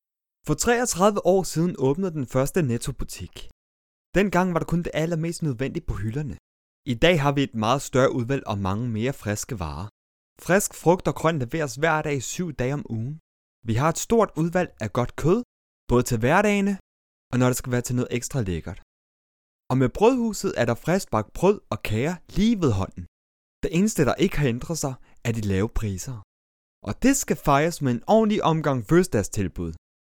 Danish, Scandinavian, Male, Home Studio, 20s-30s